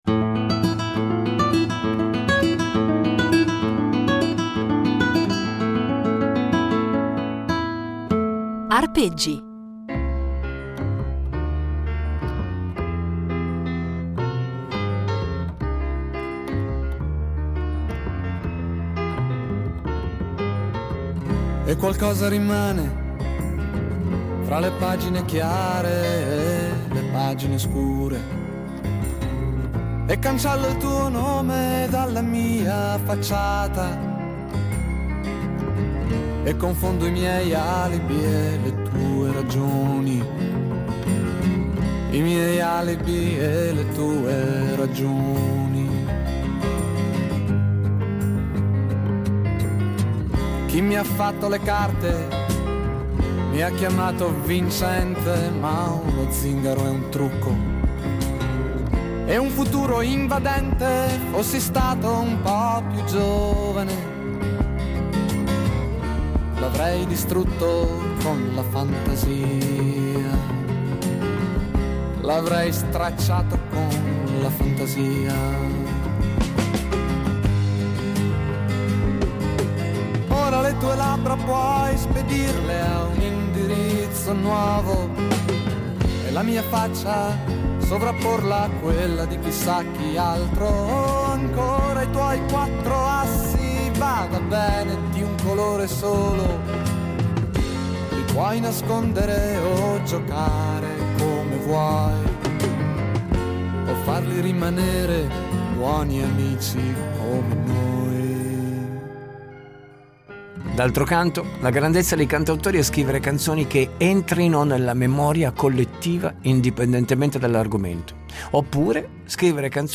sax
chitarra
versioni inedite, evocative e puramente strumentali di canzoni